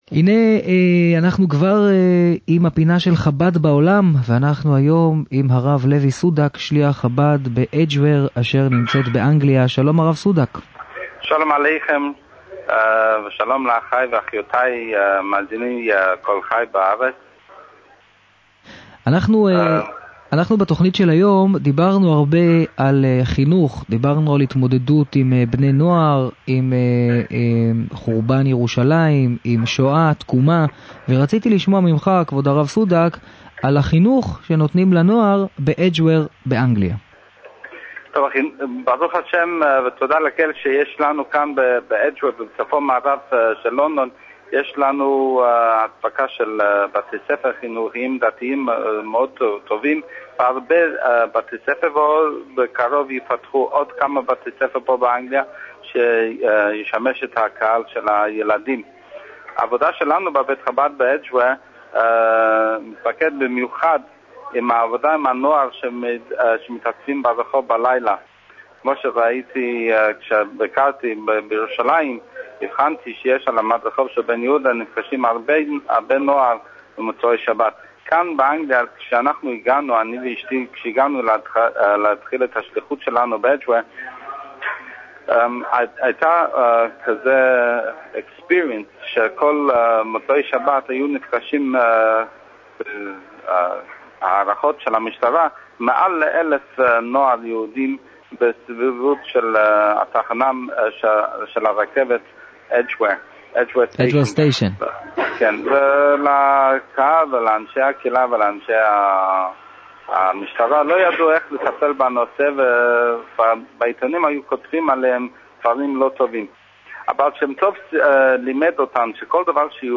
היה אורח הפינה הקבועה של ראיון עם שליח חב"ד